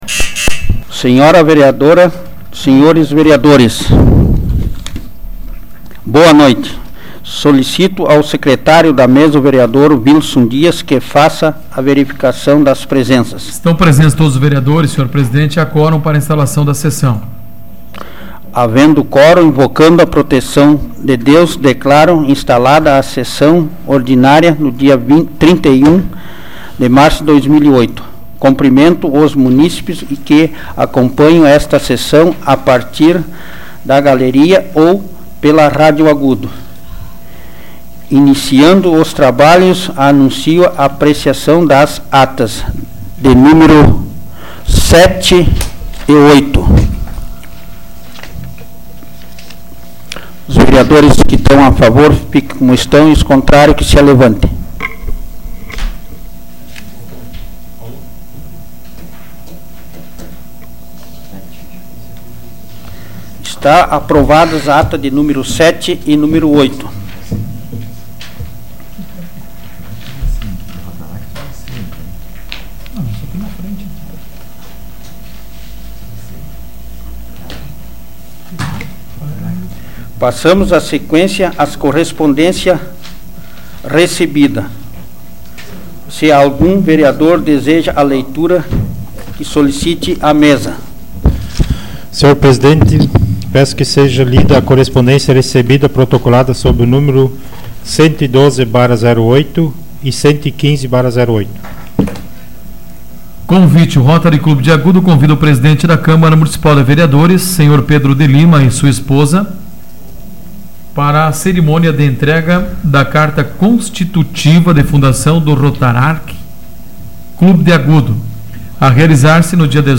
Áudio da 116ª Sessão Plenária Ordinária da 12ª Legislatura, de 31 de março de 2008